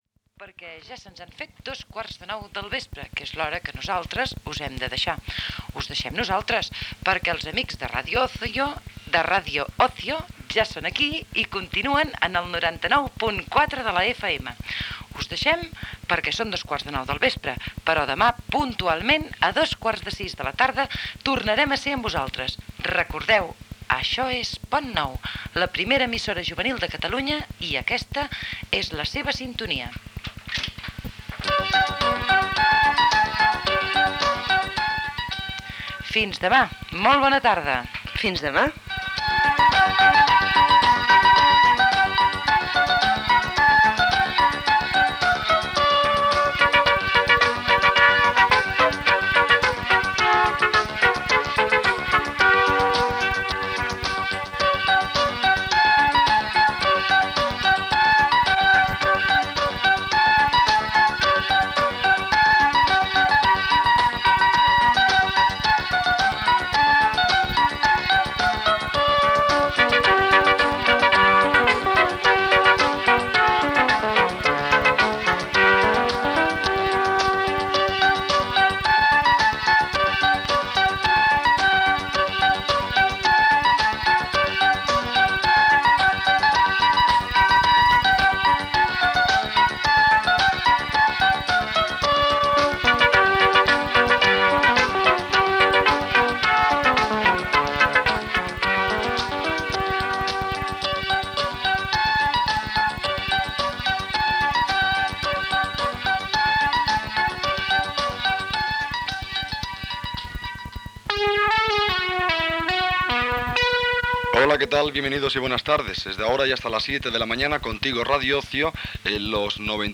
Final de la programació amb sintonia de l'emissora i inici de la programació de Radio Ocio.
Infantil-juvenil
FM